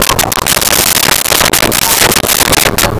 Chickens In A Barn 01
Chickens in a Barn 01.wav